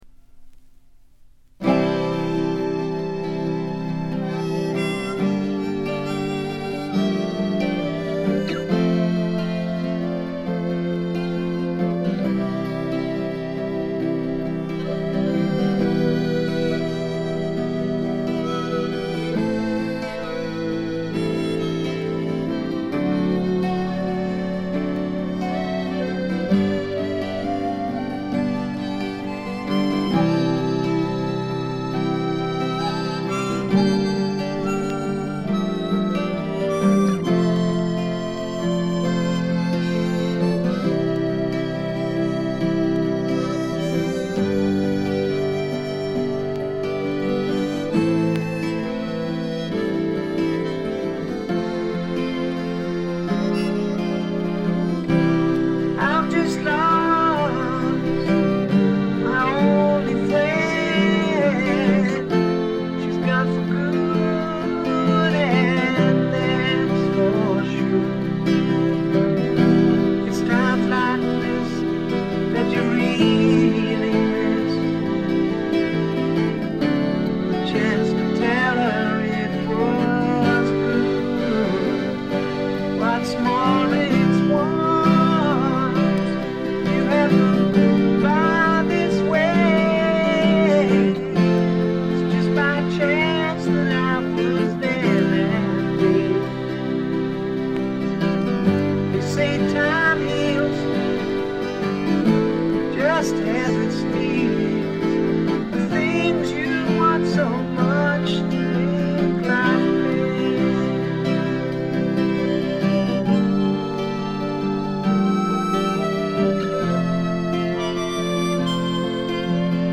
見た目に反してところどころでチリプチが出ますが大きなノイズはありません。
それに加えて激渋ポップ感覚の漂うフォークロック作品です。
この人の引きずるように伸びのあるヴォーカルは素晴らしいです。
試聴曲は現品からの取り込み音源です。